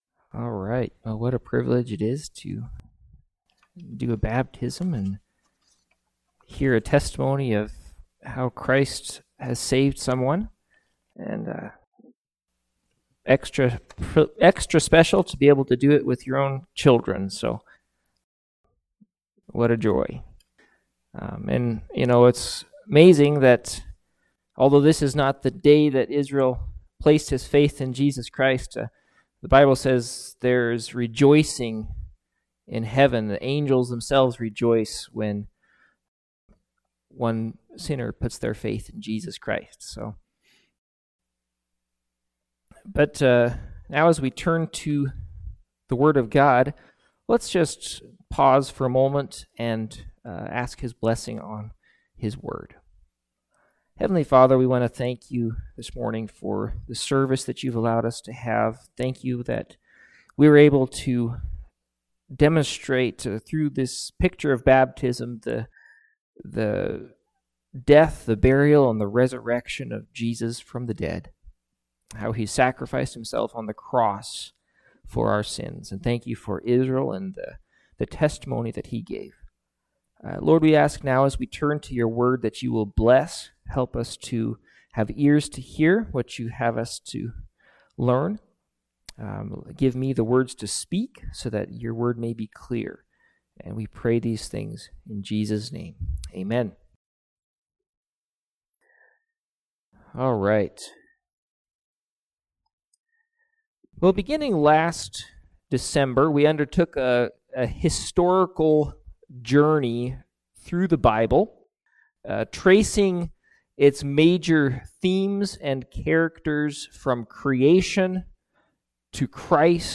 Passage: Revelation 1 Service Type: Morning Sevice